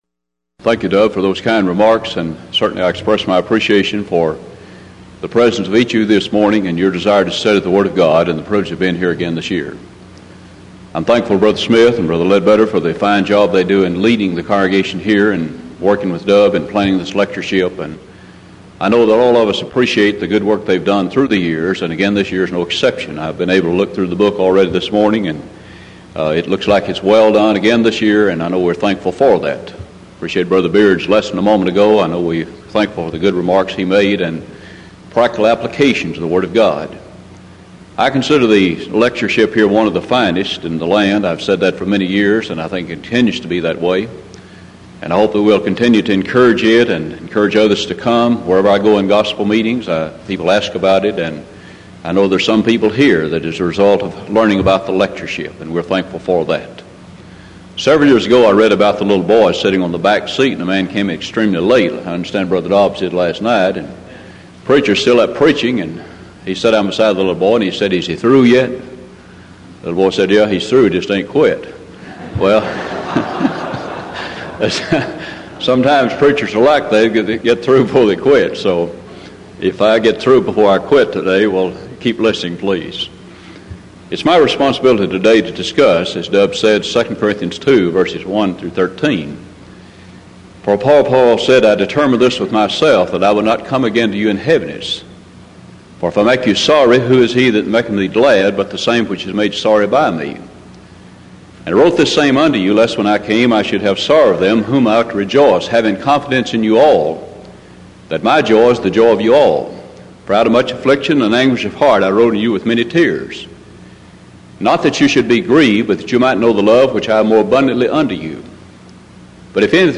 Event: 1989 Denton Lectures Theme/Title: Studies In The Book Of II Corinthians